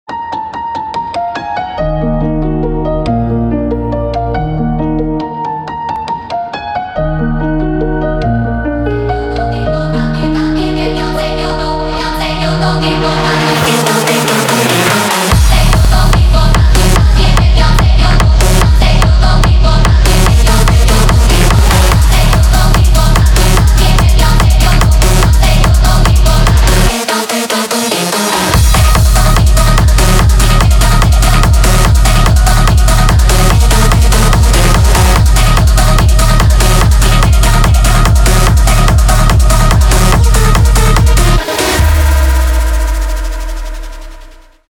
техно